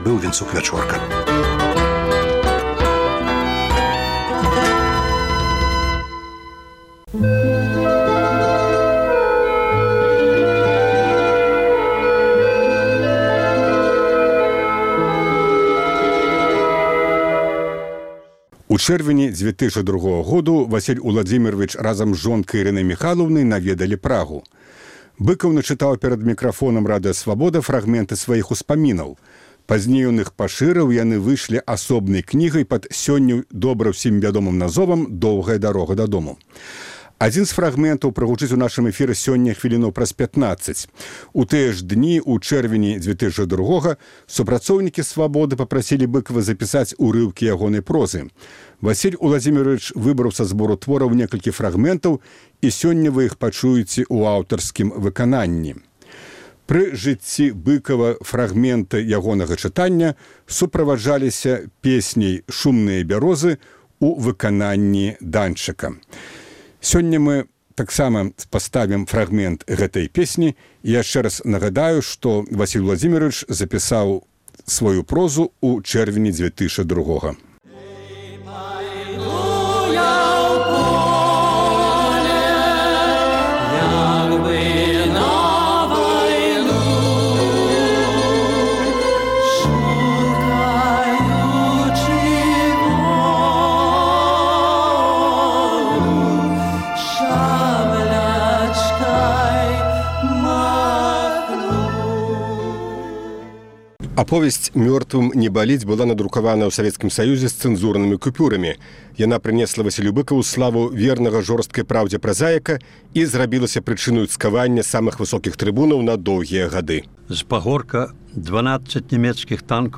Быкаў начытаў перад мікрафонам Радыё Свабода фрагмэнты сваіх успамінаў – 40 фрагмэнтаў, агульныай працягласьцю 6 гадзін 22 хвіліны.
У тыя ж дні у чэрвені 2002-га супрацоўнікі Свабоды папрасілі Быкава запісаць урыўкі ягонай прозы. Васіль Уладзімеравіч выбраў са збору твораў некалькі фрагмэнтаў, і сёньня вы можаце пачуць іх у аўтарскім выкананьні.